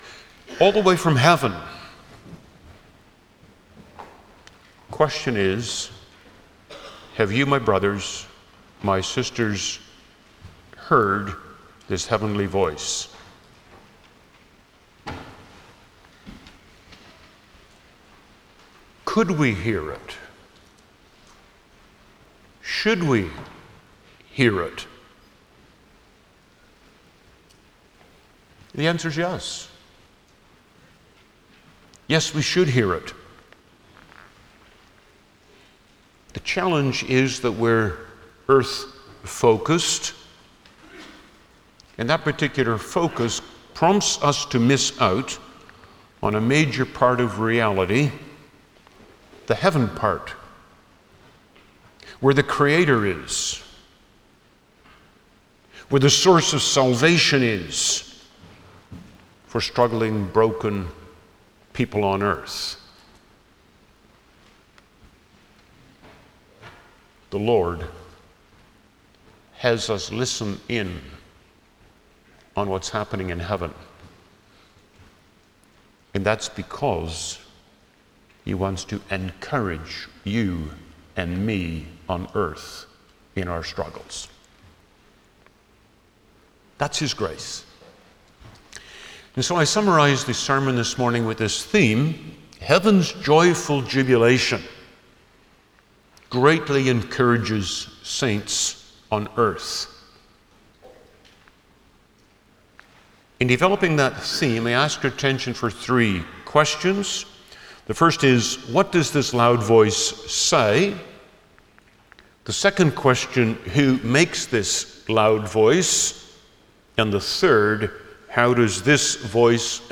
Service Type: Sunday morning
09-Sermon.mp3